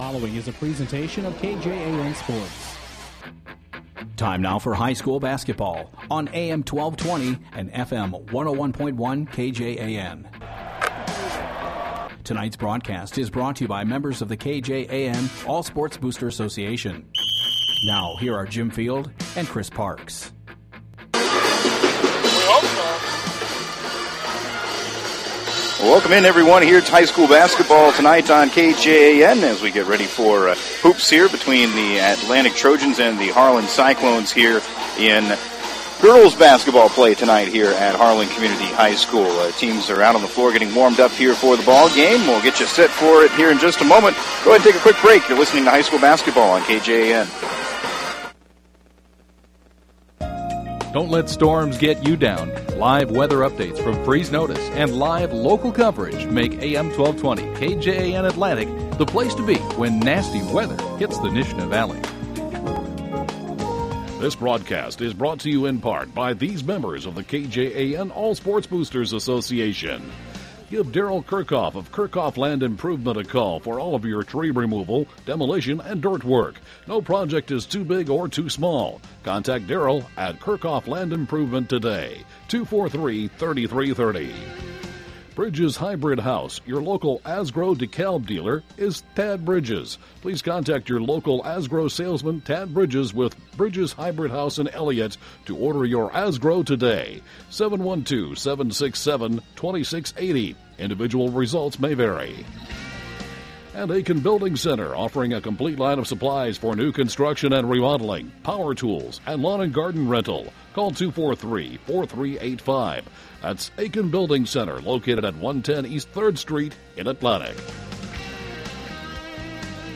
have the call of the game played at Harlan Community High School.